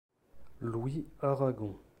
Louis Aragon (French: [lwi aʁaɡɔ̃]